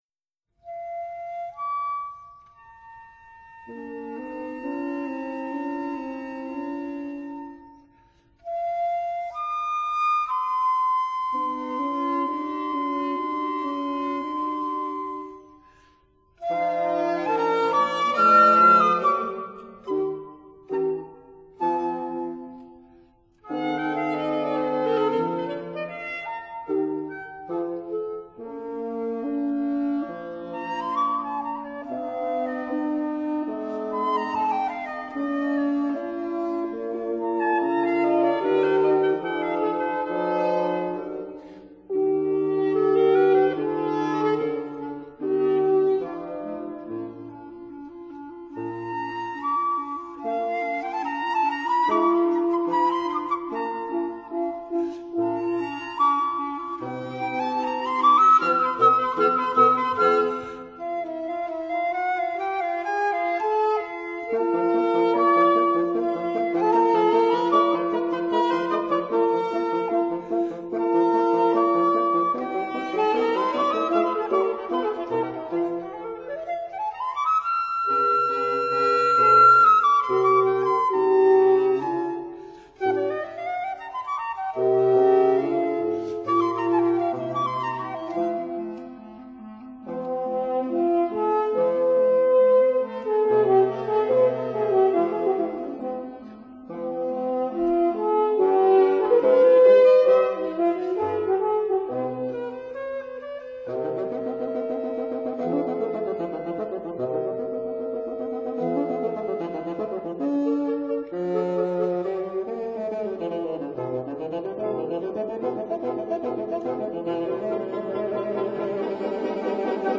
Period Instruments